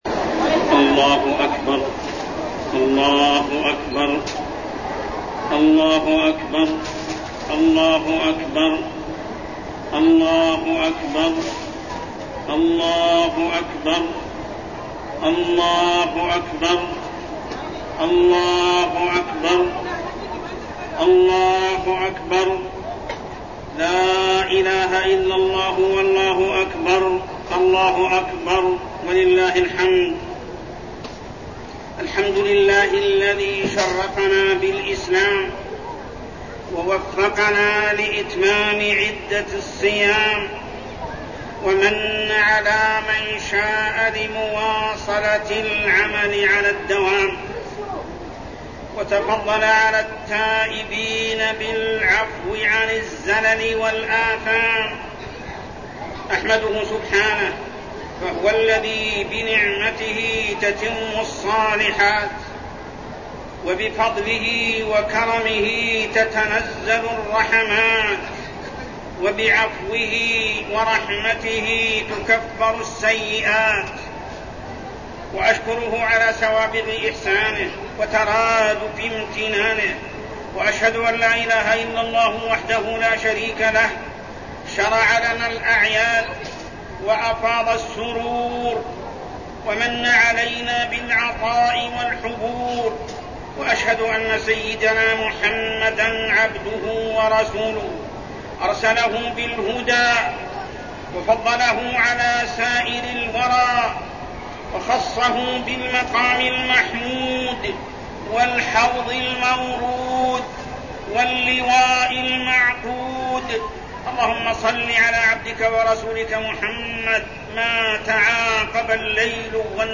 خطبة عيد الفطر-أعمال الخير بعد رمضان
تاريخ النشر ١ شوال ١٤٠٩ هـ المكان: المسجد الحرام الشيخ: محمد بن عبد الله السبيل محمد بن عبد الله السبيل خطبة عيد الفطر-أعمال الخير بعد رمضان